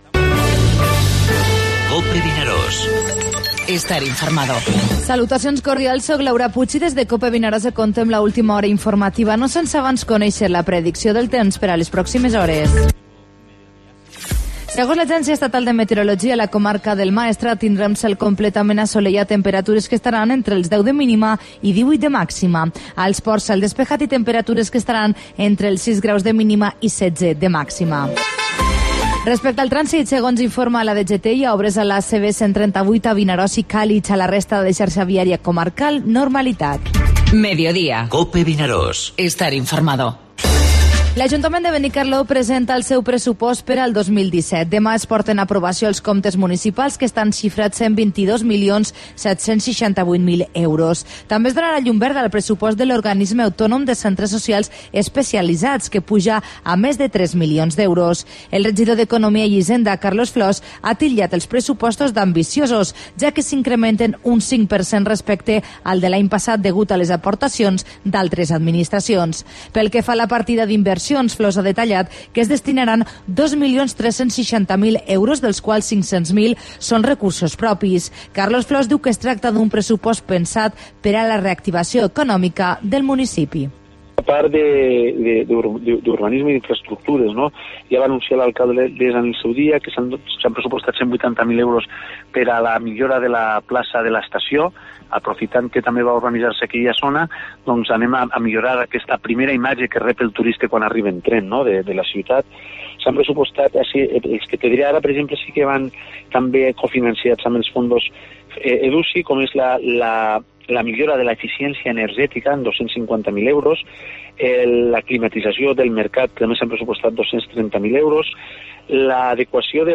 Informativo Mediodía COPE al Maestrat (30/03/17)